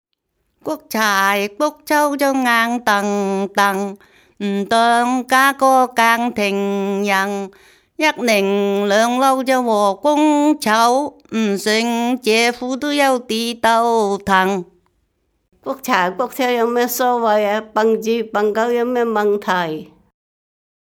區內一群長者聚在一起，輪流唱著塵封了的古老歌謠，越唱越開懷，不覺間勾勒出昔日農鄉的浮世繪。